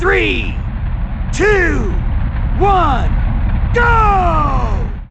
英语321倒计时音效免费音频素材下载